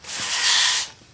sweep.wav